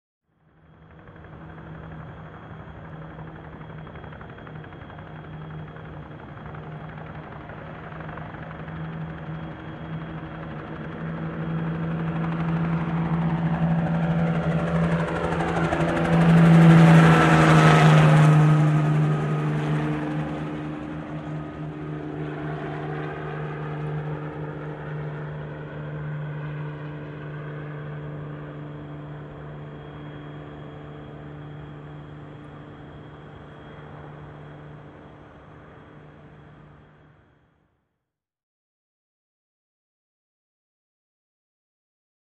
Helicopter By, Bell 47 Chopper, Long Approach, Fast By & Away, Good Blade Definition.